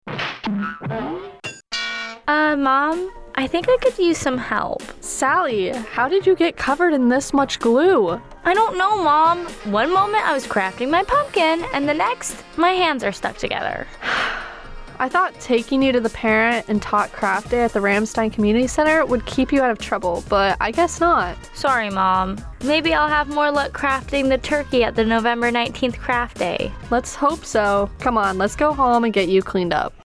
Radio Spot - Parent and Tot Turkey Craft